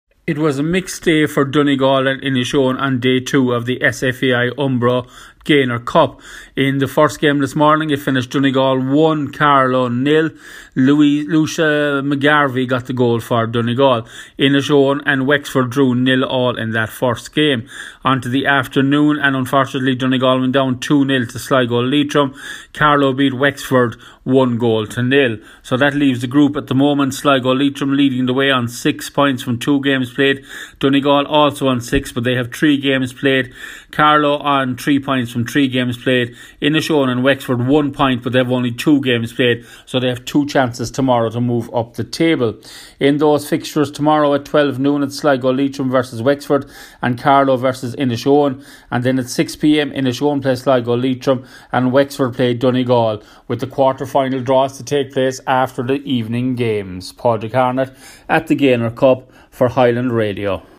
reports from the University of Limerick on  Day 2 (Wednesday) at the Gaynor Cup was for the girls of Donegal and Inishowen.